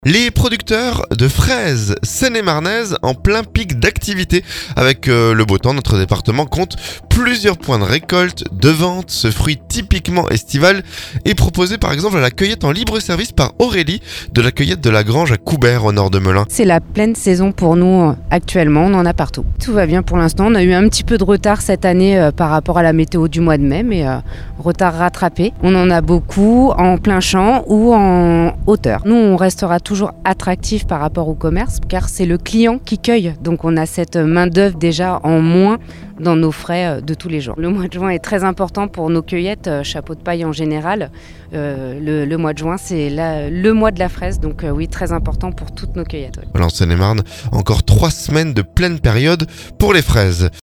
REPORTAGE - Les fraises de Seine-et-Marne prêtes à être cueillies